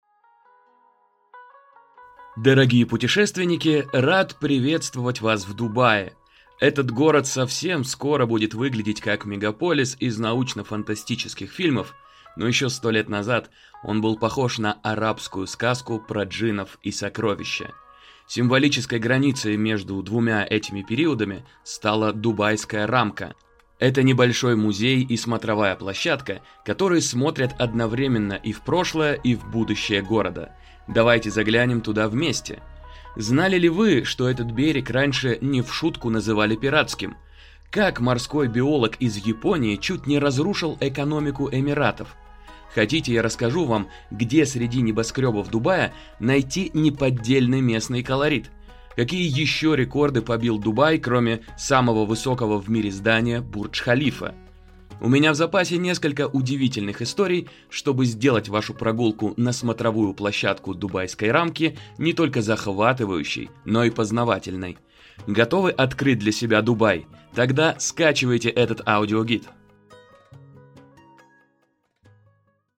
Дубайская рамка с аудиогидом TouringBee: город в одном взгляде